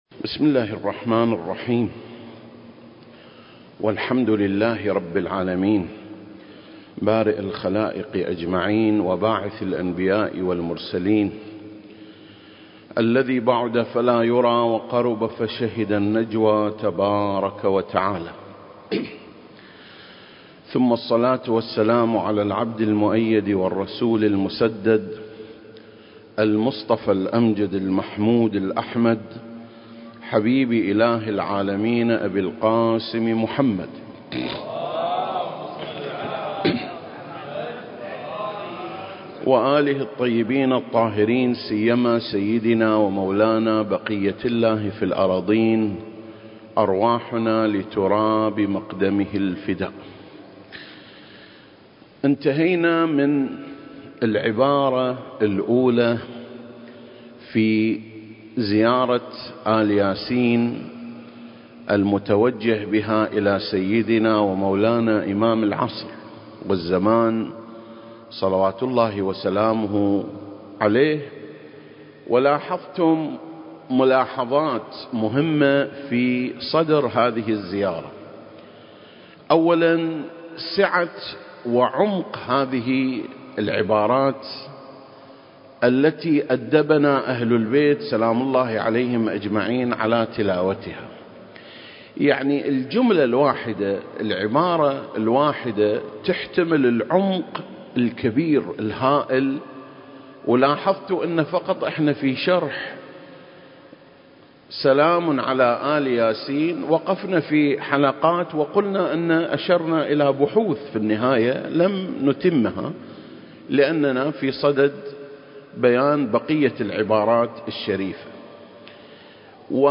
سلسلة: شرح زيارة آل ياسين (23) - يا داعي الله (1) المكان: مسجد مقامس - الكويت التاريخ: 2021